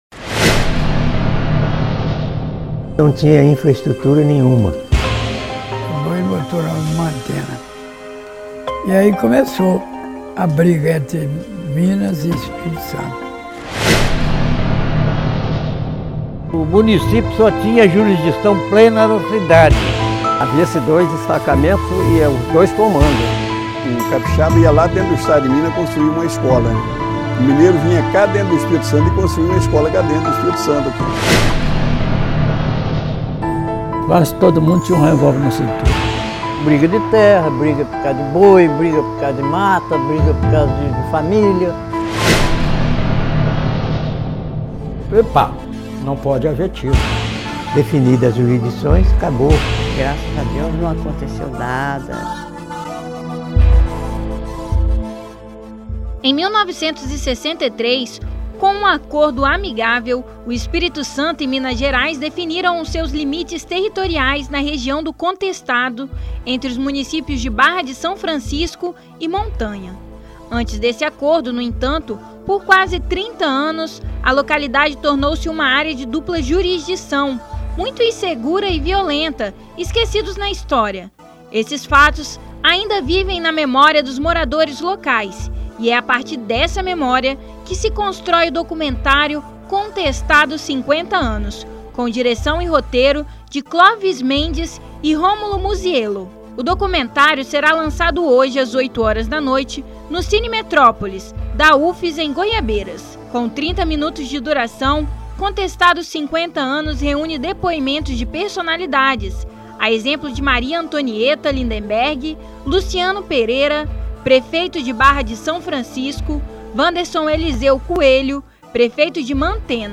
Confira a matéria que foi transmitida no Revista Universitária.